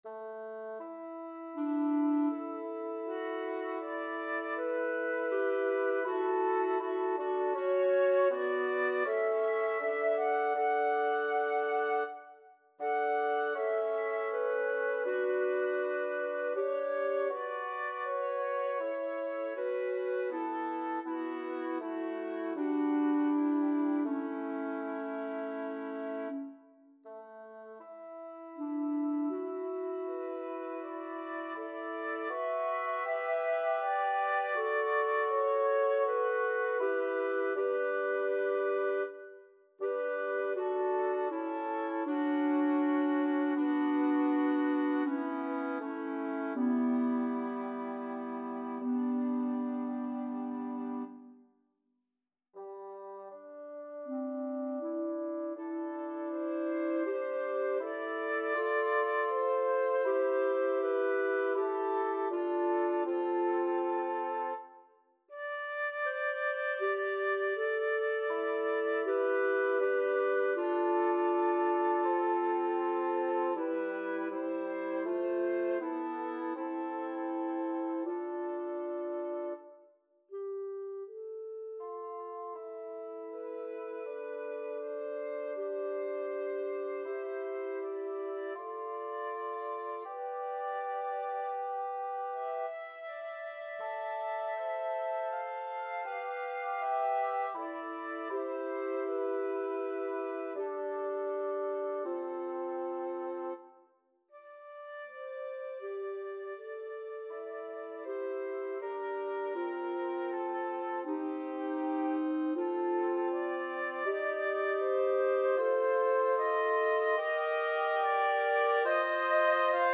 The following are are some of my compositions realized in midi.
Lauda, Lauda Anima, written for SSA choir but realized in midi woodwind trio: